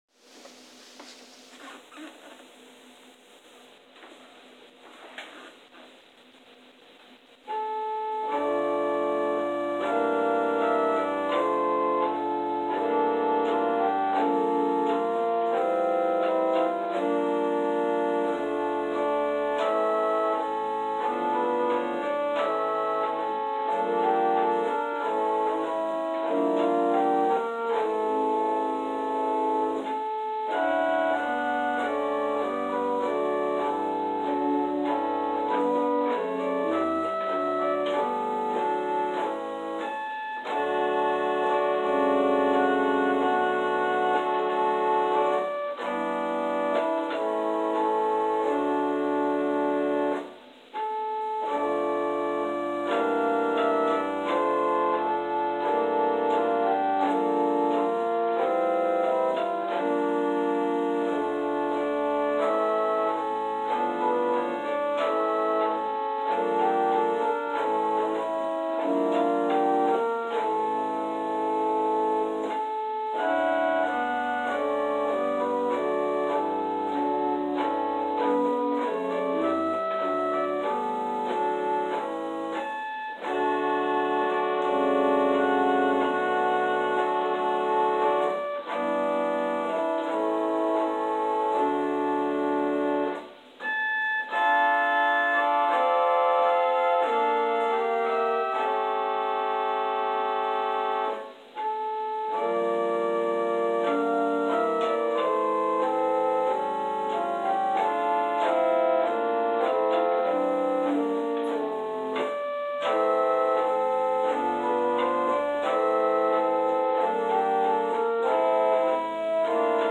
Interlude: “Offertory for Epiphany” – Wihla Hutson